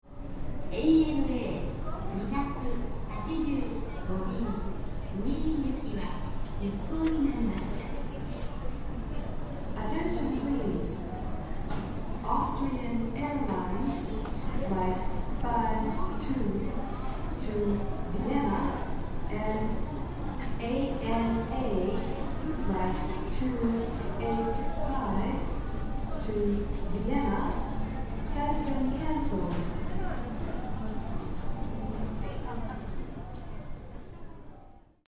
今のところほぼ生音。
〜 日本語英語〜 注目して下さ い、Attention Please！（女声） 00.35